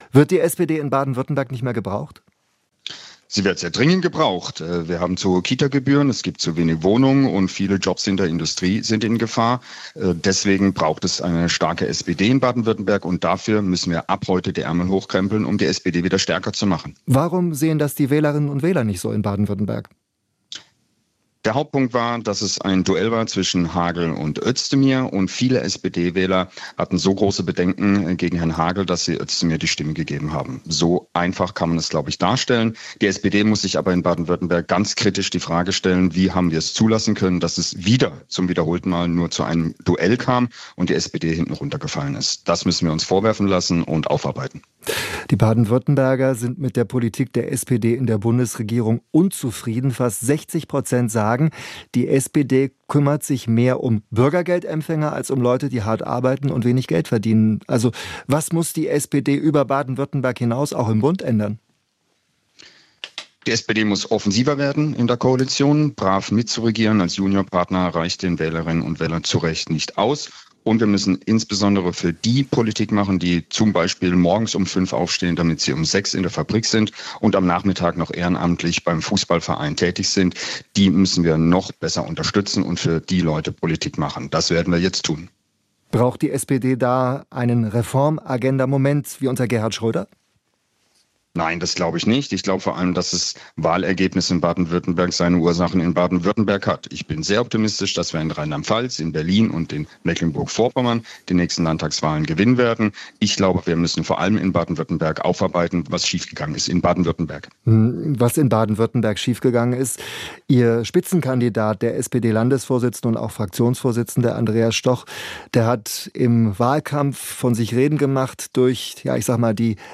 Interview mit SPD-Abgeordnetem Röderer: "Es war ja letztendlich fast wie eine Oberbürgermeisterwahl"
Trotzdem hat der SPD-Landtagsabgeordnete Jan-Peter Röderer sein Landtagsmandat verloren. Dazu äußert er sich im SWR-Interview.